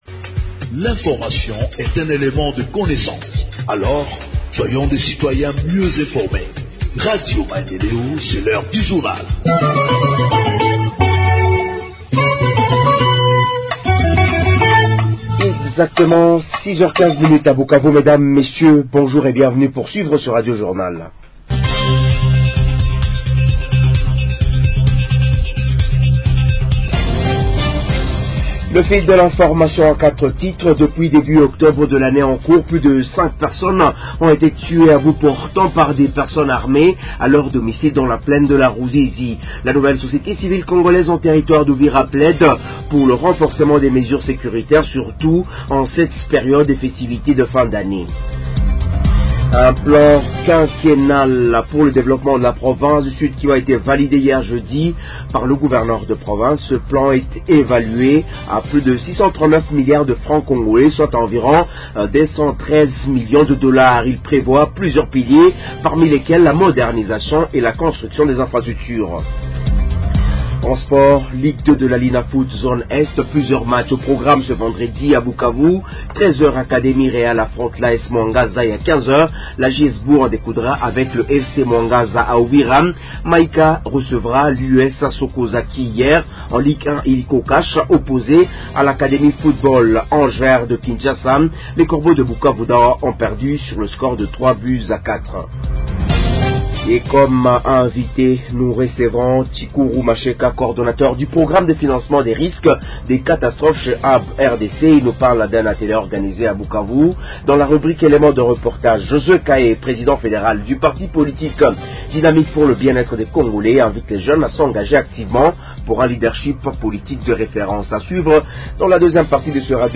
Journal en Français du 13 Décembre 2024 – Radio Maendeleo